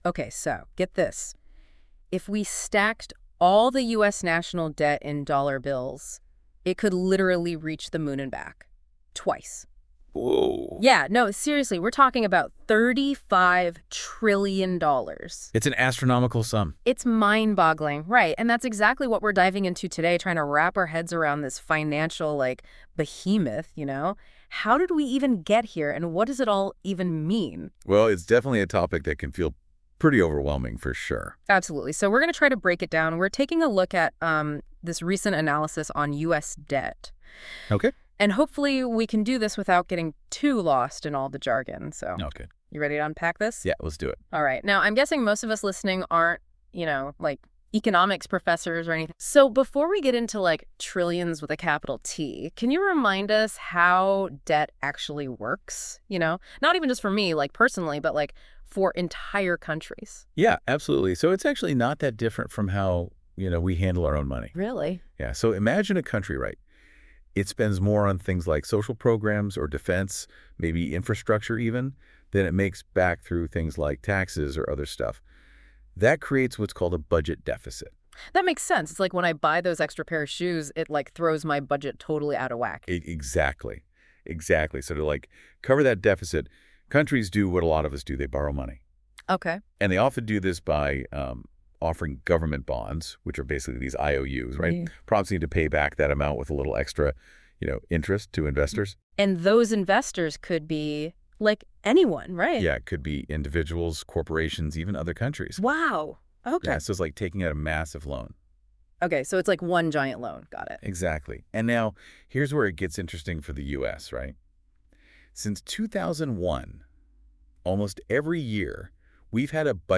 Disclaimer: While the voices you hear in The Future Thought Exchange are powered by advanced AI, they can sometimes mispronounce words, misunderstand concepts, or provide information that may not be entirely accurate.